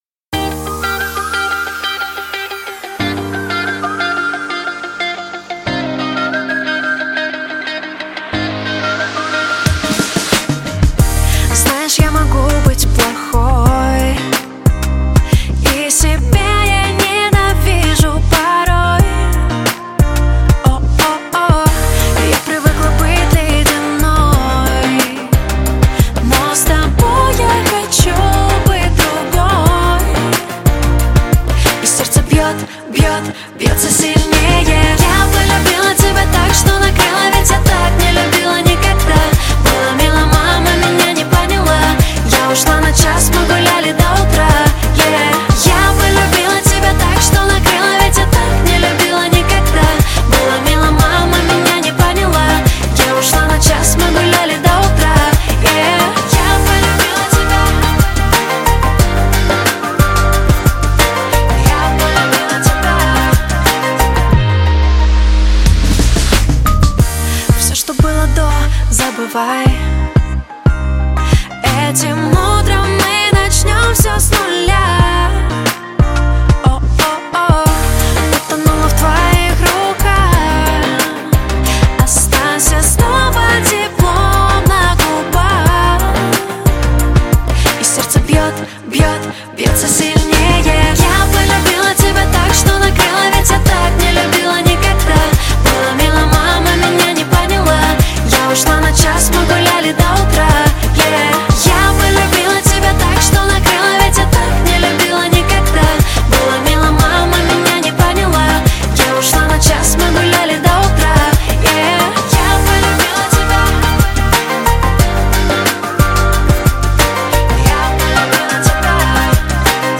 Поп-музыка